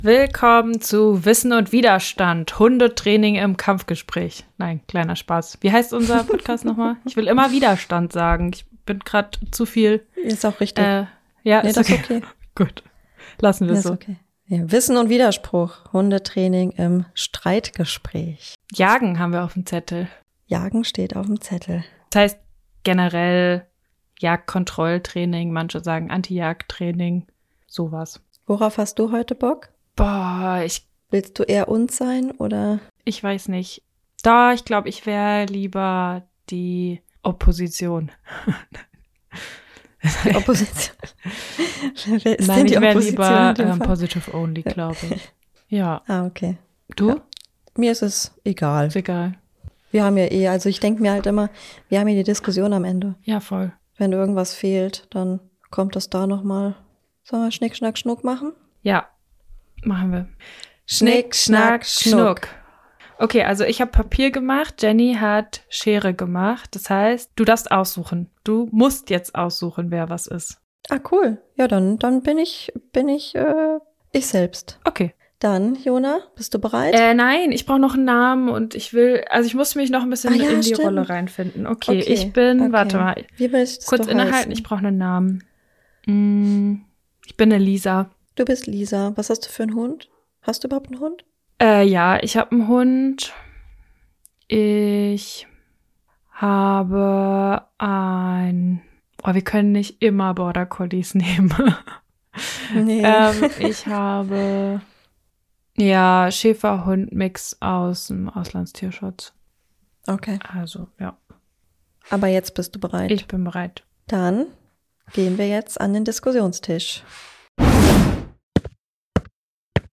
Wenn der Timer klingelt, endet die Diskussion und wird von uns eingeordnet.